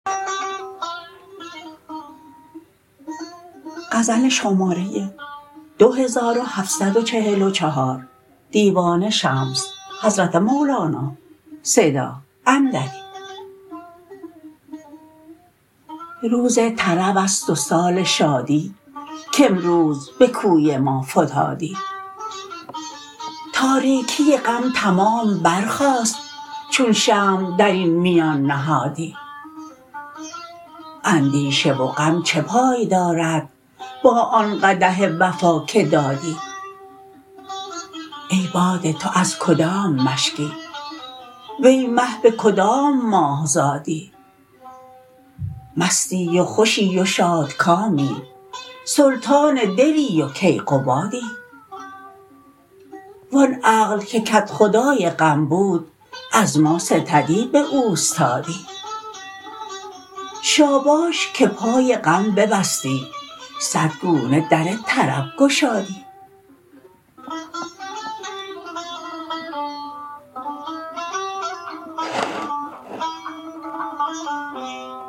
غزل شمارهٔ ۲۷۴۴ از (مولانا » دیوان شمس » غزلیات) را با خوانش